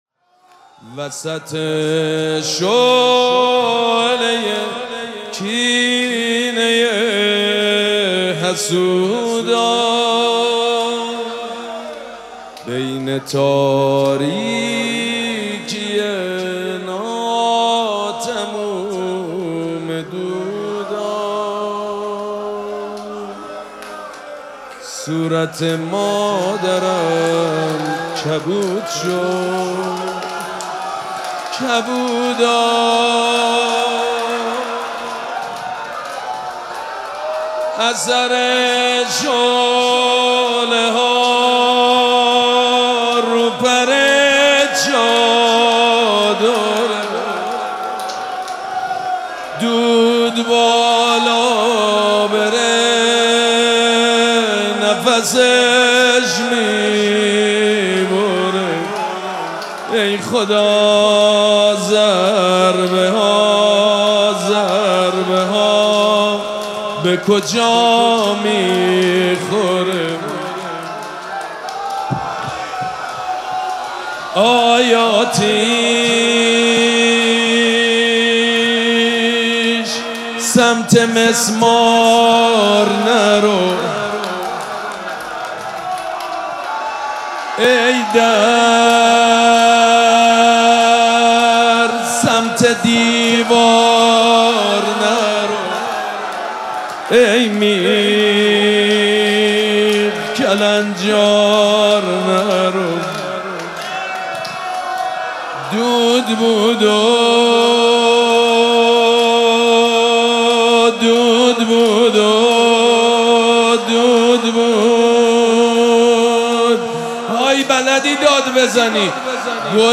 شب دوم مراسم عزاداری دهه دوم فاطمیه ۱۴۴۶
روضه
مداح
حاج سید مجید بنی فاطمه